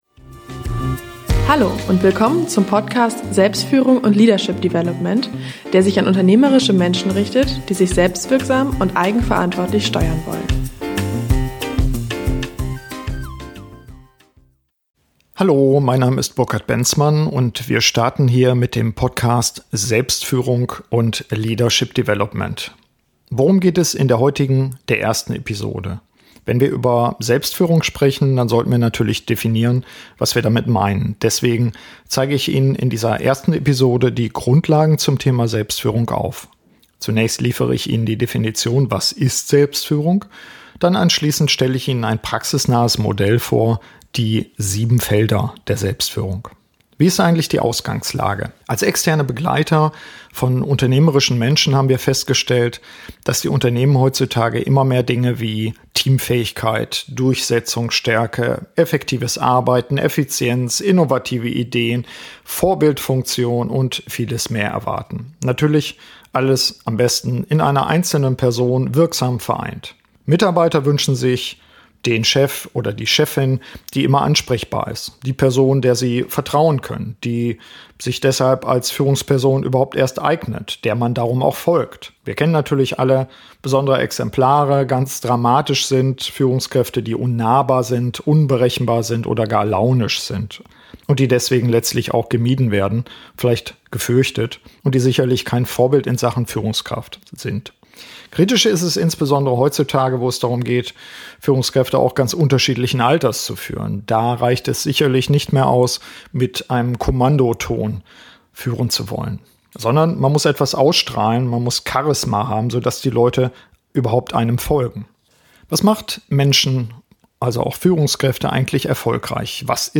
(Neue Aufnahme, neue Qualität - Stand: 29.07.)